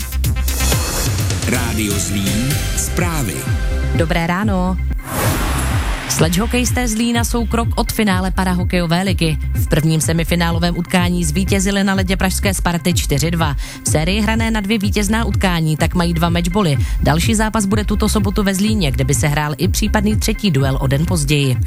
Reportáž po 1. semifinálovém utkání ČPHL 2018/2019